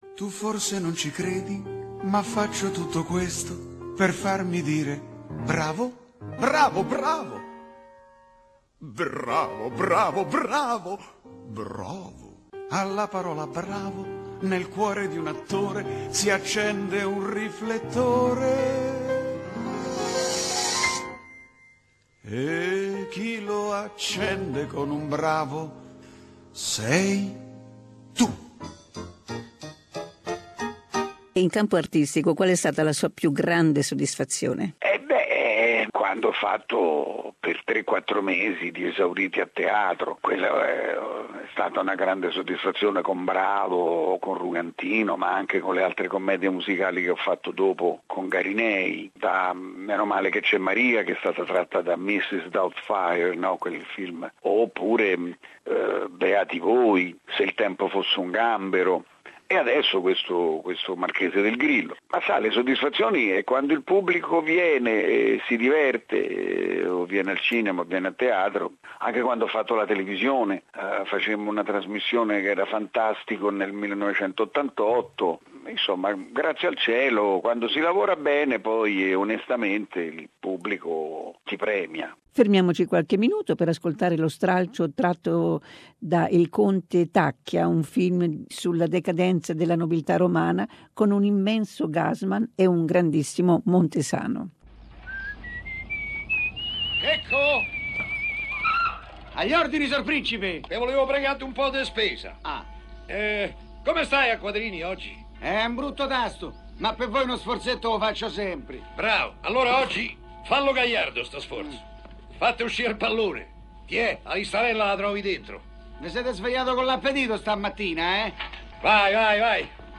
Second part of an interview with popular Italian actor, Enrico Montesano.